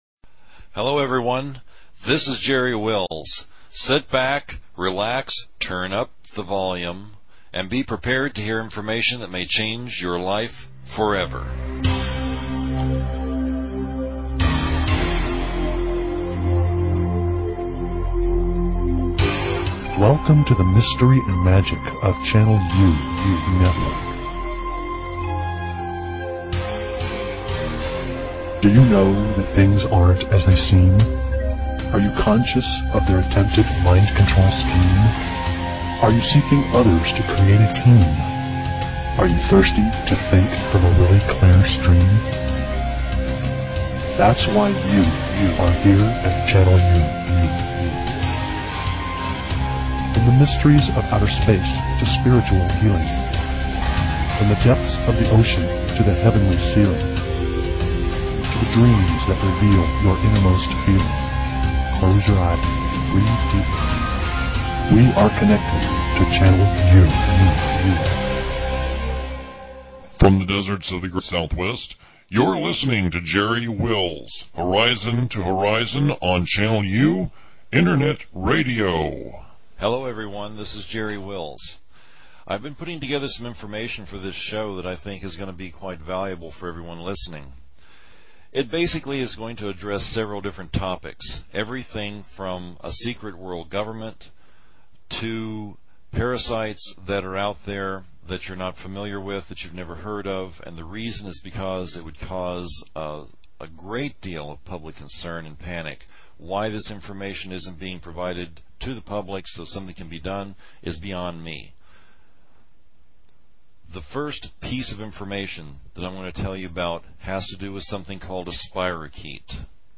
Talk Show Episode, Audio Podcast, Channel_U and Courtesy of BBS Radio on , show guests , about , categorized as